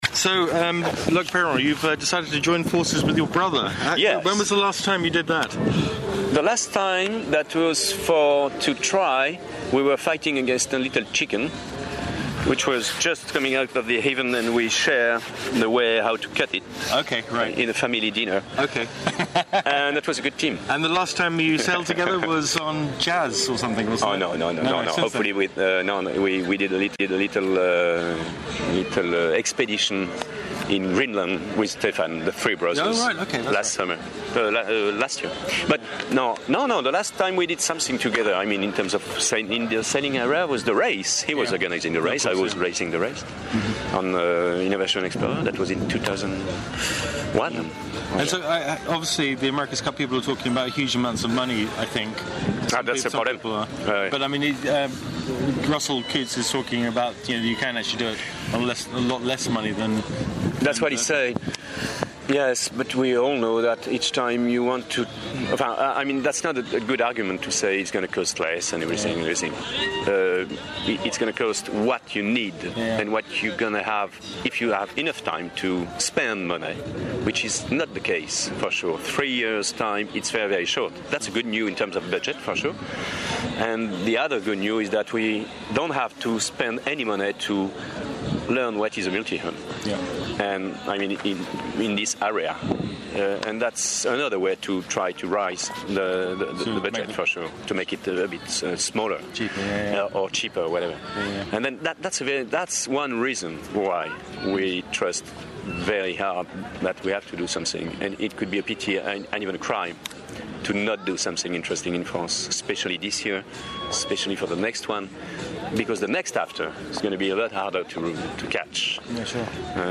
Loick Peyron audio interview | The Daily Sail